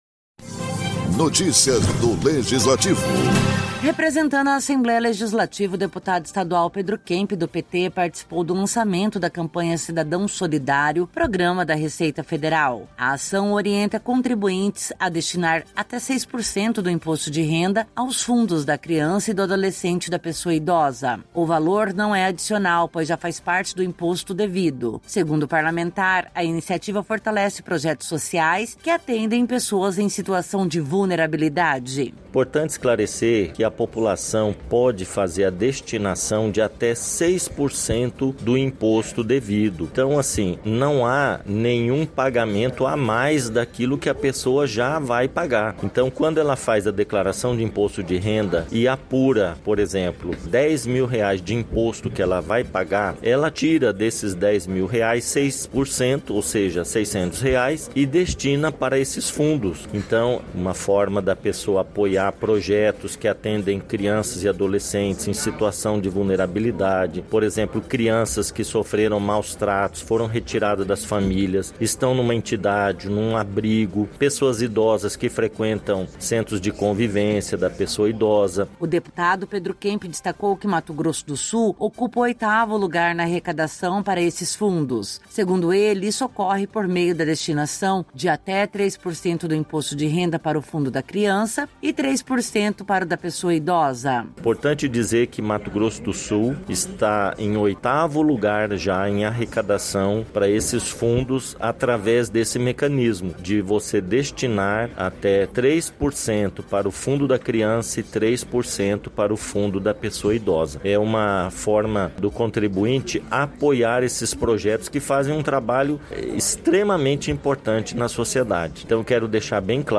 A iniciativa orienta contribuintes a destinarem parte do Imposto de Renda aos fundos da Criança e do Adolescente e da Pessoa Idosa. Em discurso, o parlamentar destacou a importância da contribuição para manter e fortalecer projetos sociais.